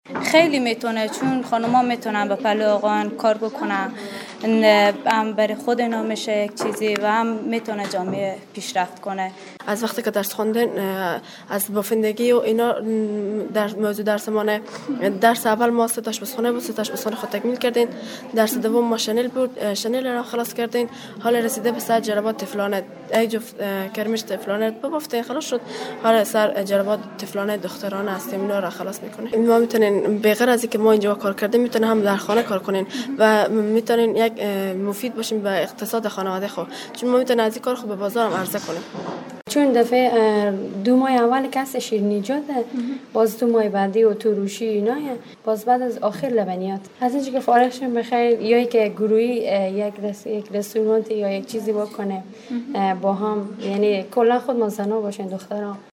برخی از زنانی که از این دوره‌های کارآموزی بهره می‌گیرند چنین می‌گویند.